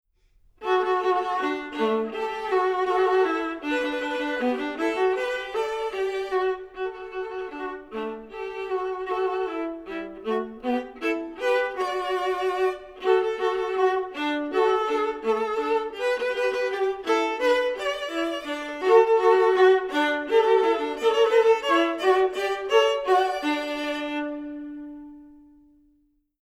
Voicing: Viola Duet